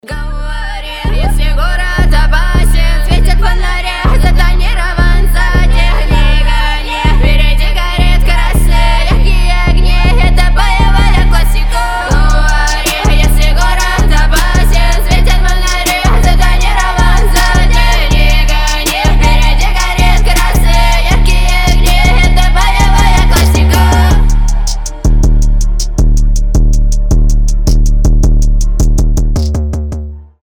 • Качество: 320, Stereo
женский голос
мощные басы
качающие
взрывные